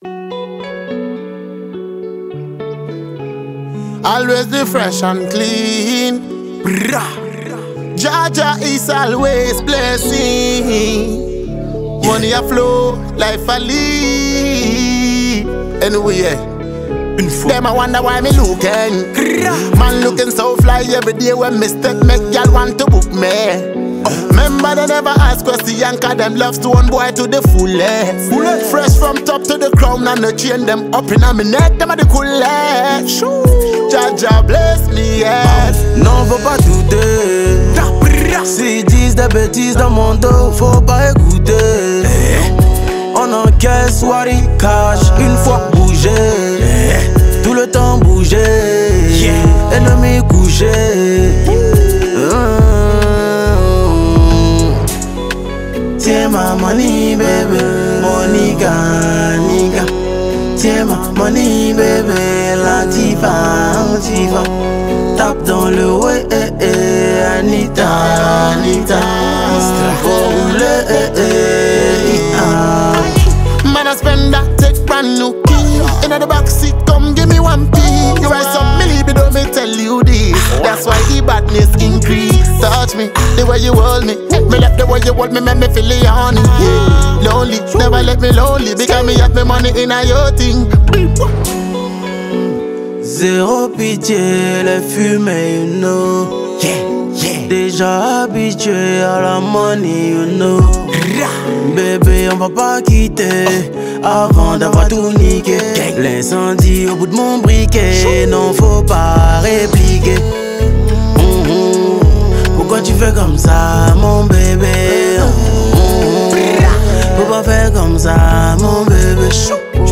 Dancehall
blends catchy rhythms with captivating lyrics